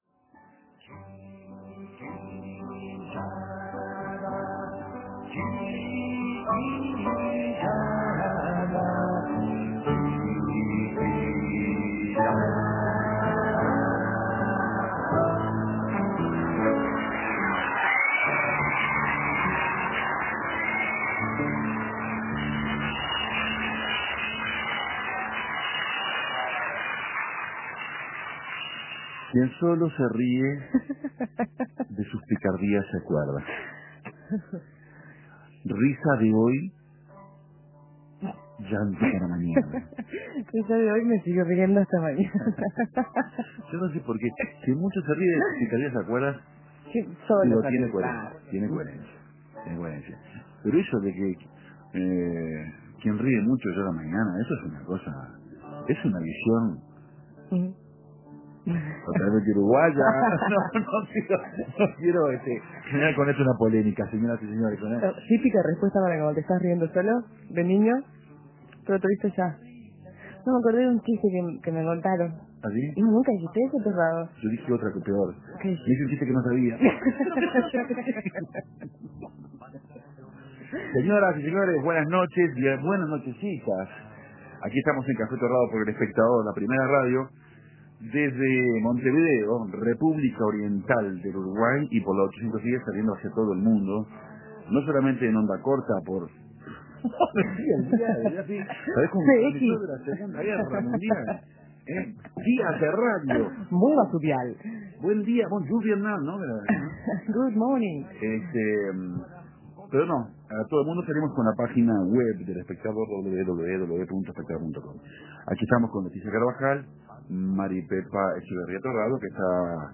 Los recuerdos de la niñez y los juegos que compartíamos, o inventábamos, invadió el estudio de Café Torrado. La audiencia participó con sus recuerdos en estos lugares comunes que son los juegos de la infancia, que marcaron nuestra niñez de alguna manera.